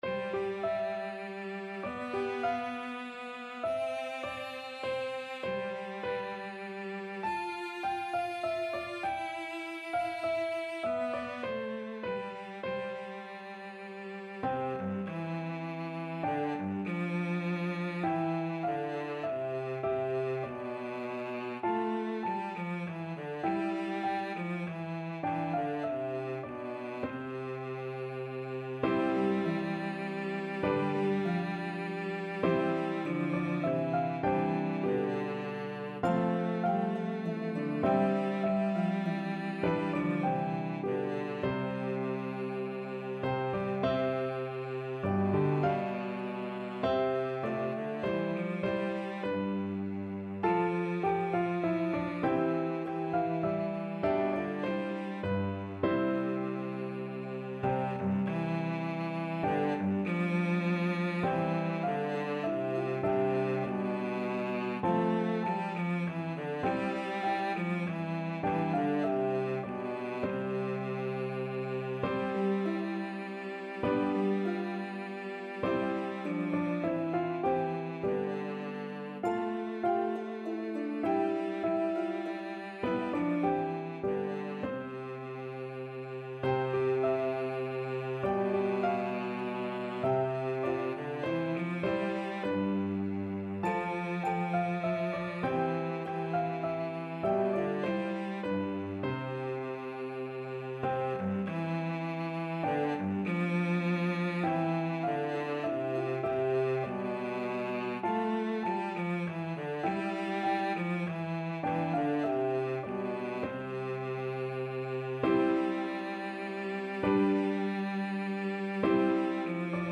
traditional round
Harp, Piano, and Cello version